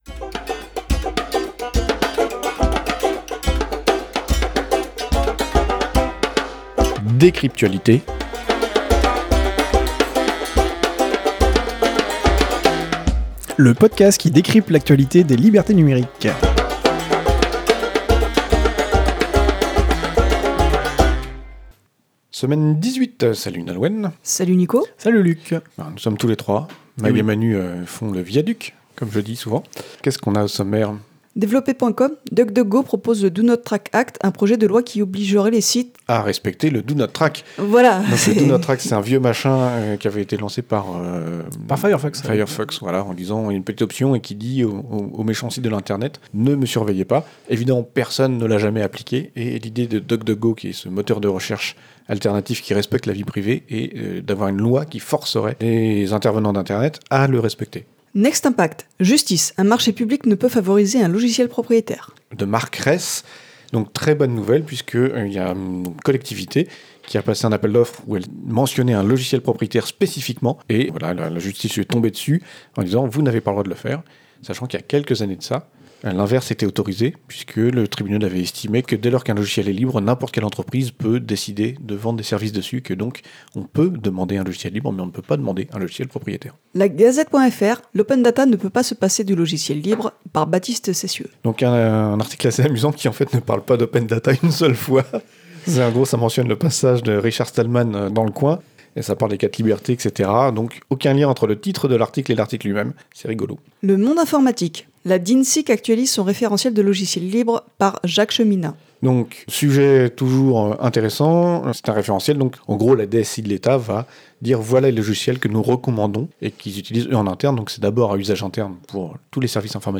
Revue de presse pour la semaine 18 de l’année 2019